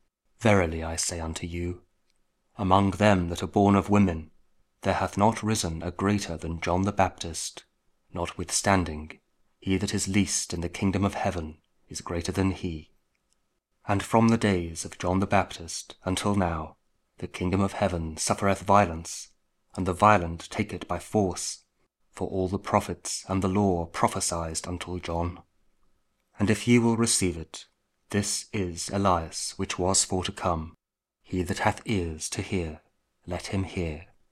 Matthew 11: 11-15 | King James Audio Bible KJV | Daily Bible Verses | Advent | Week 2 Thursday